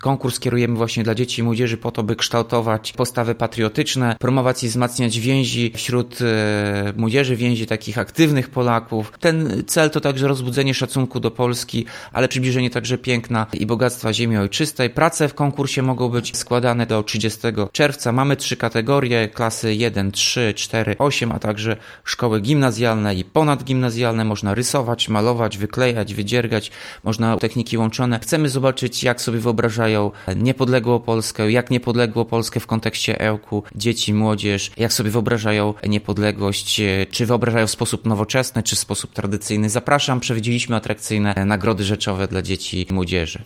Te najlepsze wybierane będą z podziałem na kategorie wiekowe, mówi Tomasz Andrukiewicz prezydent Ełku.